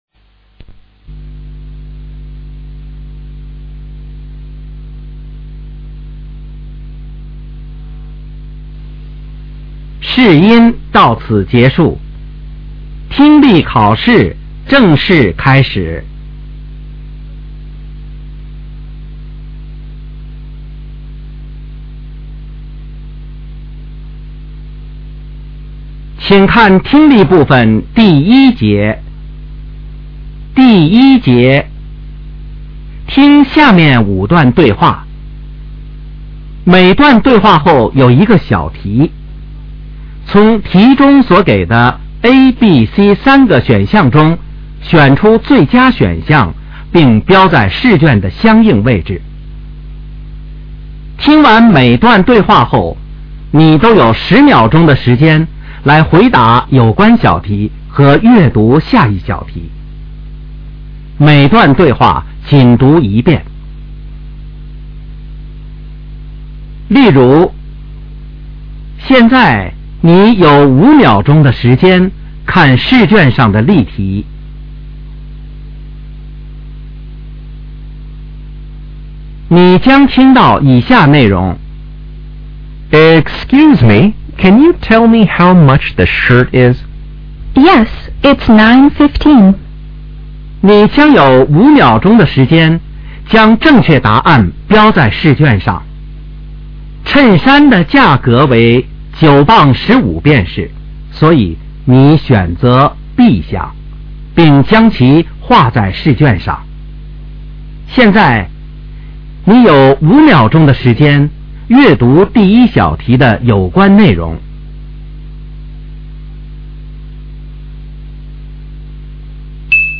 Directions: In Part A, you will hear ten short conversations between two speakers. At the end of each conversation ,a question will be asked about what was said.